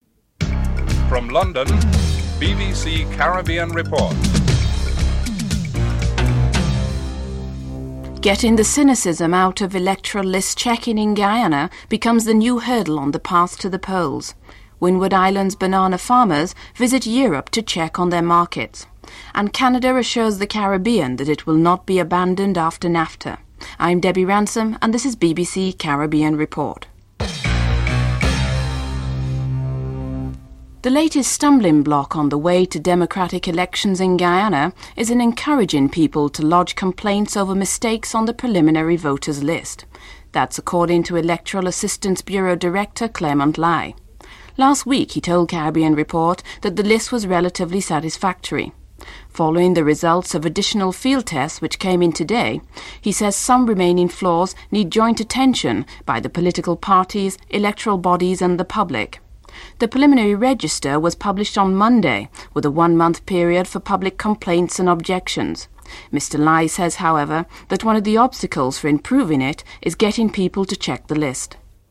1. Headlines (00:00-00:31)
5. The Canadian Government assures the Caribbean that relations with the region would not suffer should it enter into the North America Free Trade Agreement (NAFTA). Assistant Minister for Caribbean and Latin American Affairs, Stanley Gooch comments (10:47-13:15)